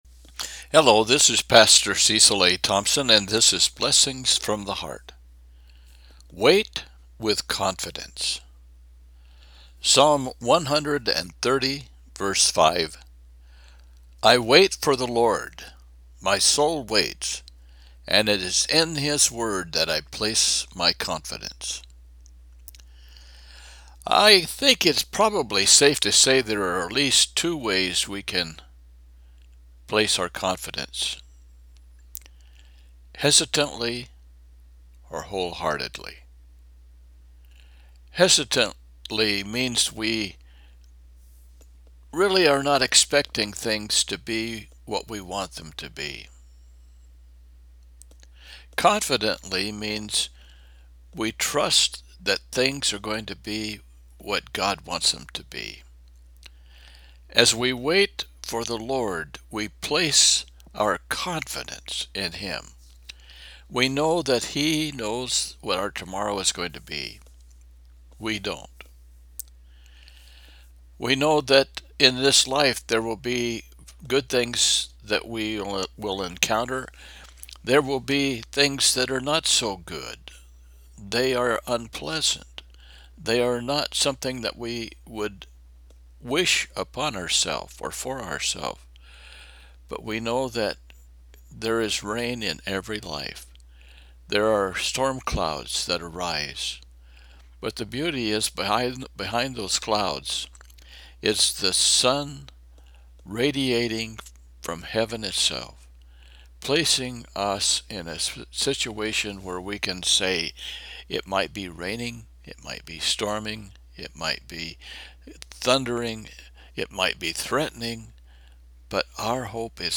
The Bible in One Year & Prayer